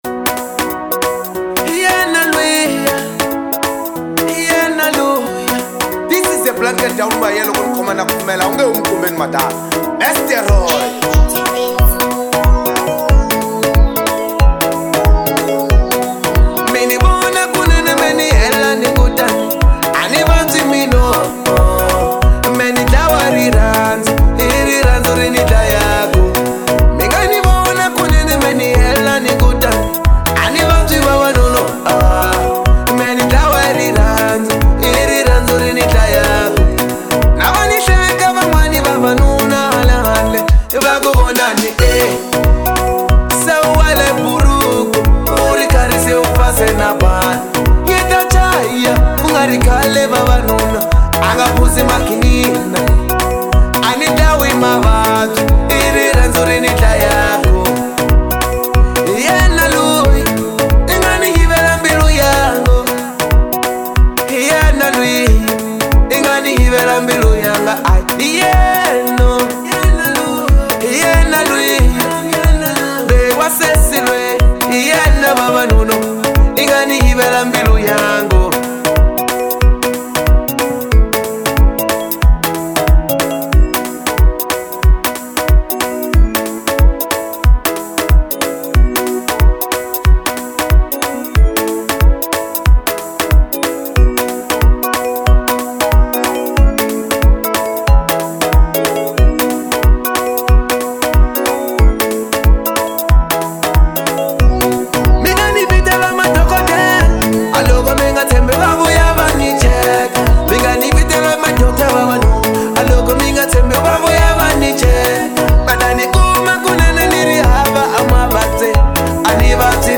04:25 Genre : Marrabenta Size